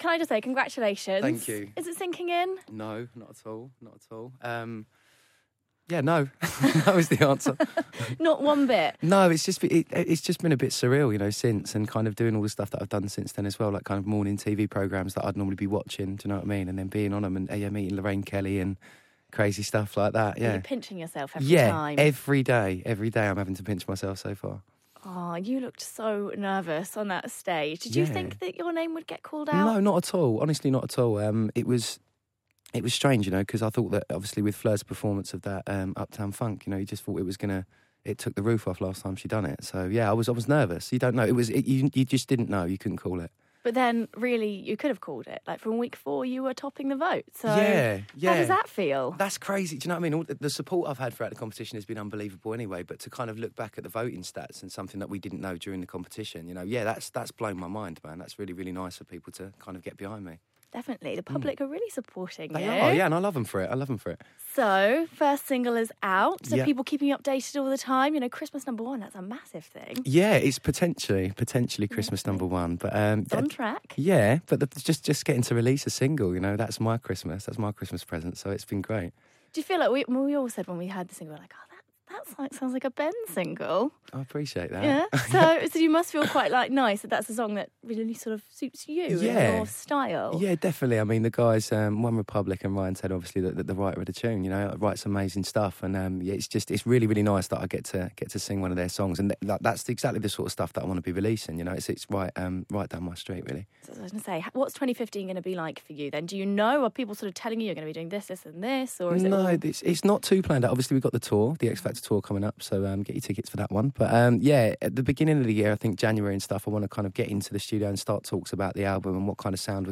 Ben Haenow Interview